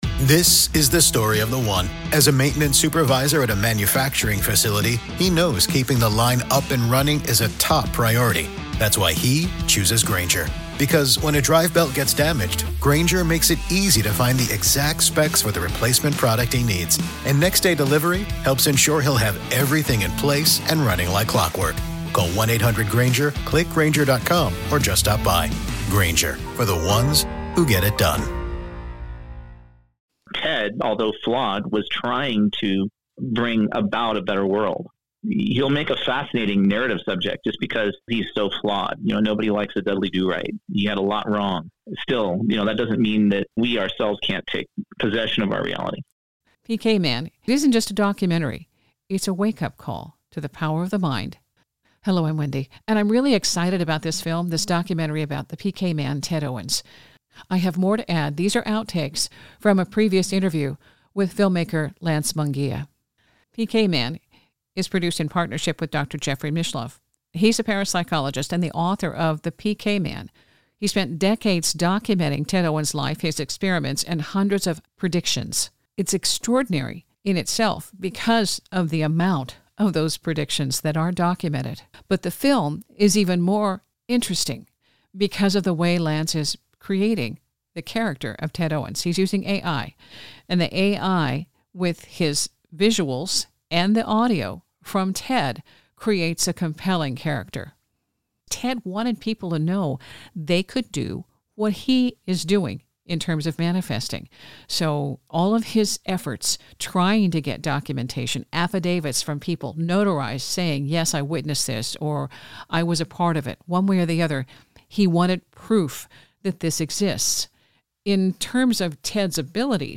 A follow up - with bonus outtakes from my interview